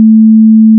Farming (8): plant_seed, water_crop, harvest, dig, scythe, mine, chop, cow
**⚠  NOTE:** Music/SFX are PLACEHOLDERS (simple tones)
dig.wav